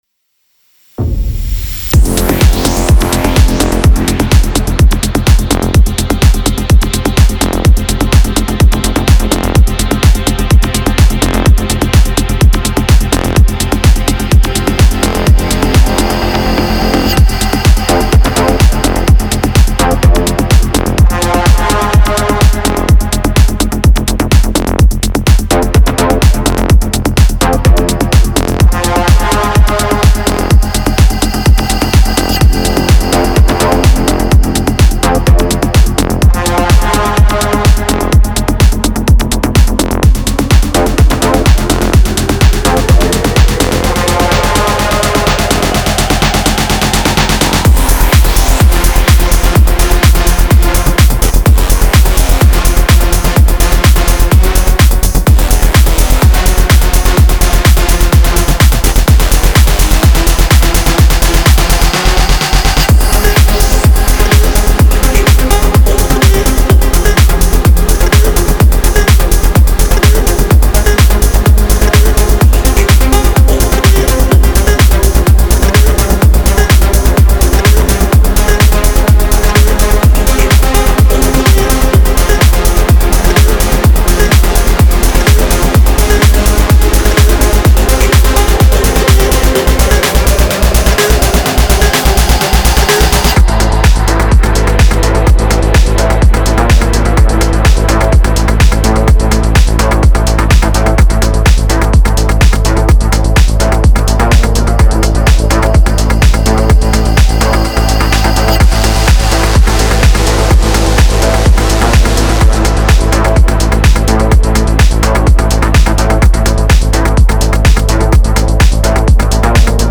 Genre:Melodic Techno
Bass Loops: 昇る太陽の鼓動のように脈打つ低音 (40)
Synth Loops: 広がる空を感動的に描くシンセサウンド (114)
Atmosphere Loops: 深みと静けさで音楽を包み込むアンビエント (40)
Vox Loops: トラックに人間味を吹き込む囁きの声 (22)
Drum Loops: 覚醒のリズムを駆り立てるドラムサウンド
デモサウンドはコチラ↓
126 BPM